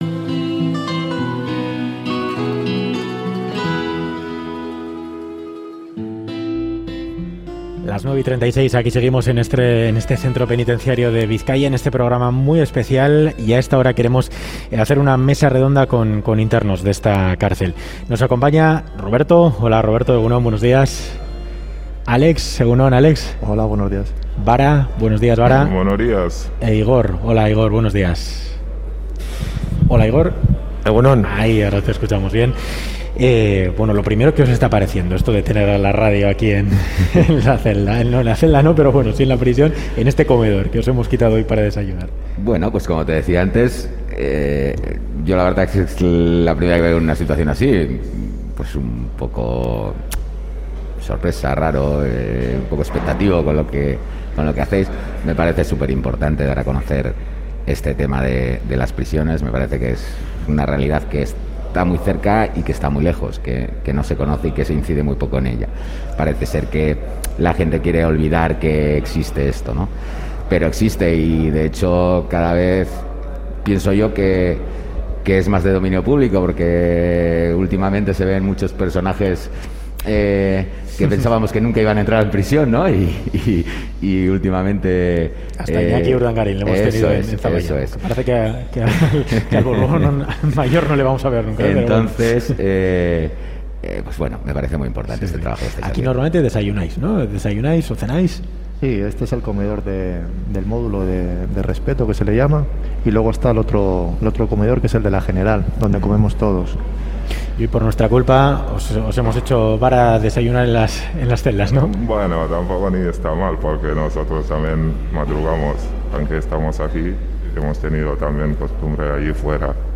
Mesa redonda